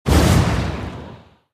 soceress_skill_shockwave_02_explosion.mp3